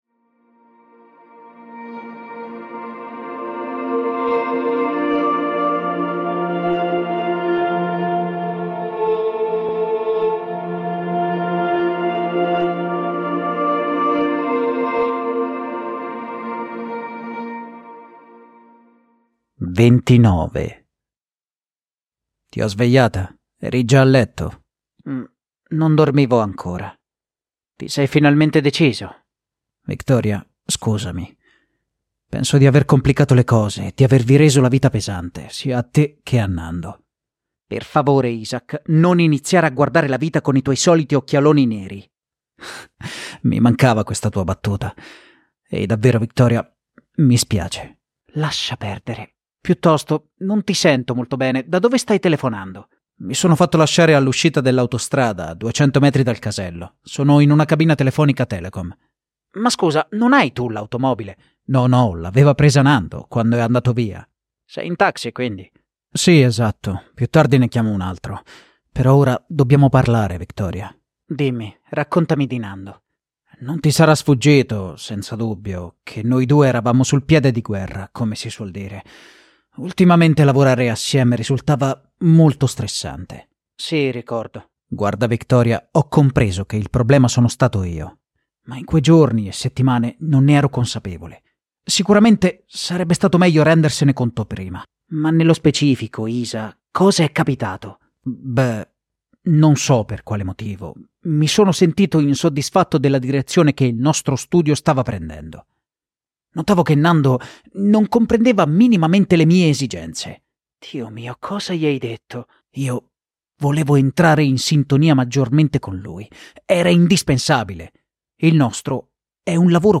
Audiolibri integrali, sempre gratis.